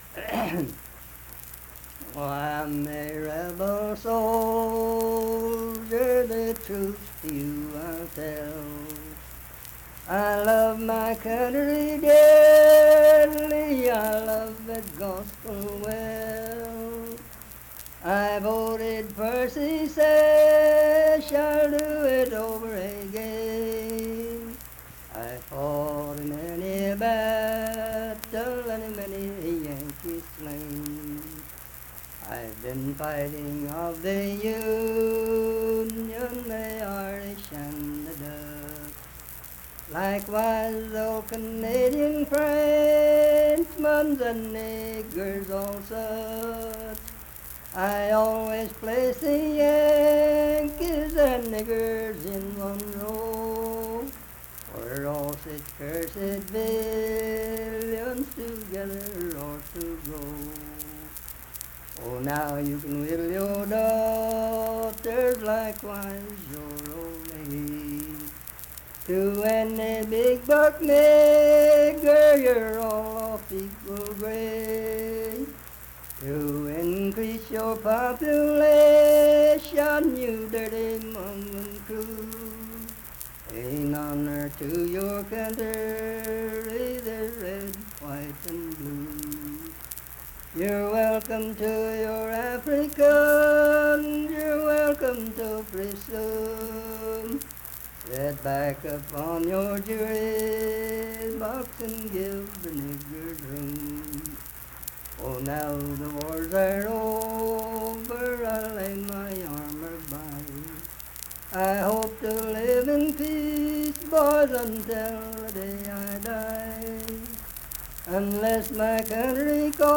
Unaccompanied vocal music performance
War and Soldiers, Political, National, and Historical Songs
Voice (sung)